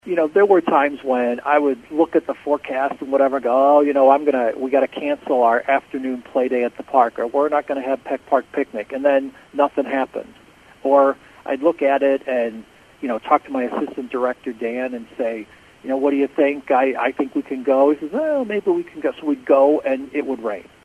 WCMY News